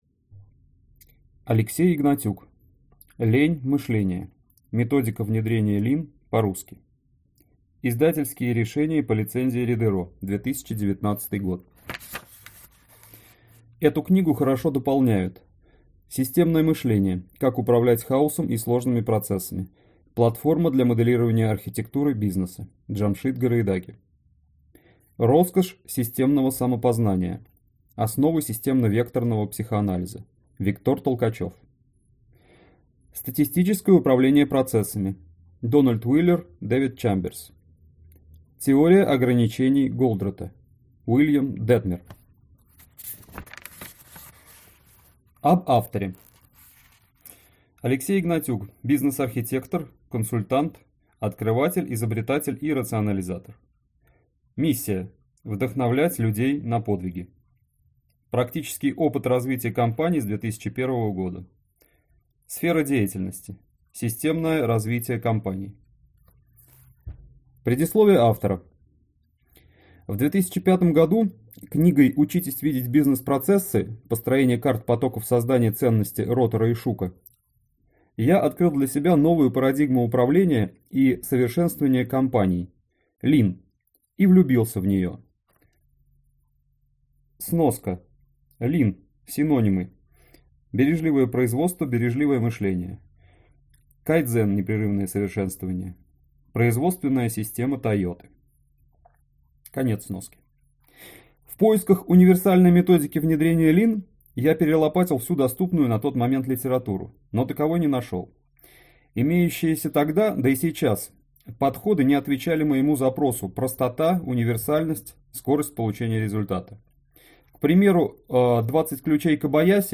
Аудиокнига Лень-мышLEANие. Методика внедрения LEAN по-русски | Библиотека аудиокниг